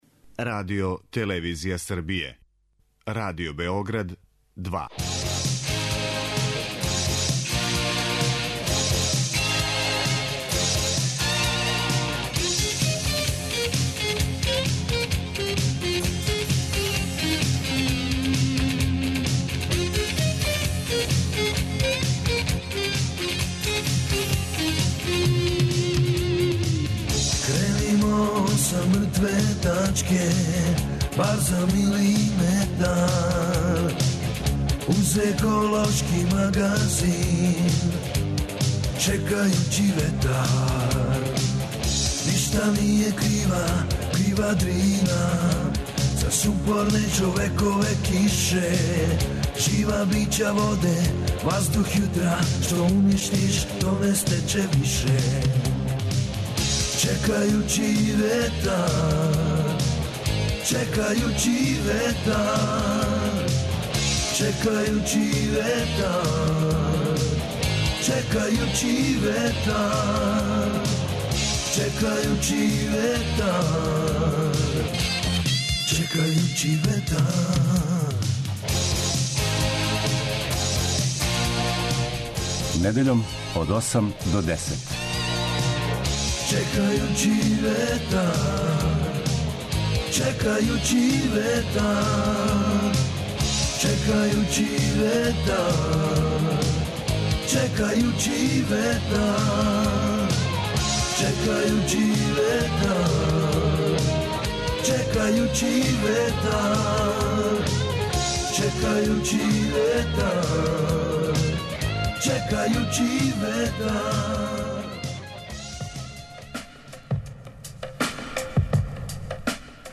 Екипа Радио Београда посетила је Зрењанин и однела пун комби чепова, које су запослени сакупљали последњих месеци.